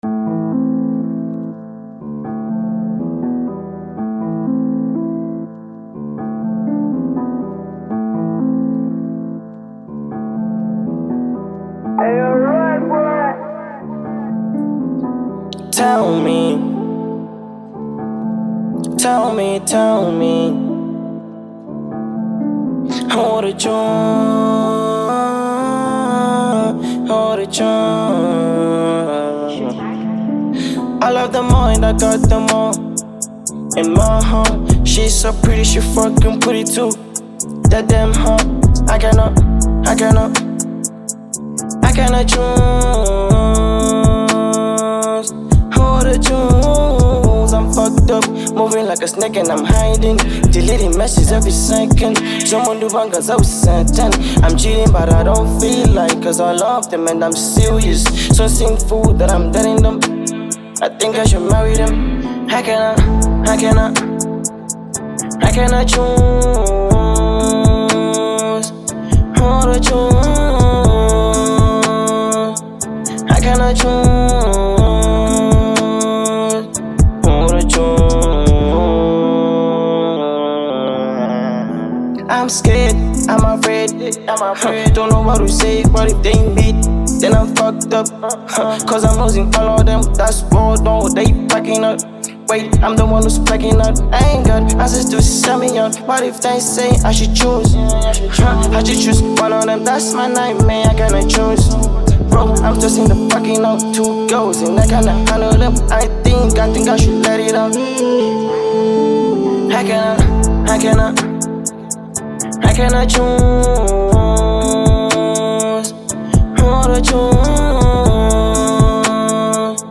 Genre : Hiphop/Trap
The beat hits hard, but the message hits even harder.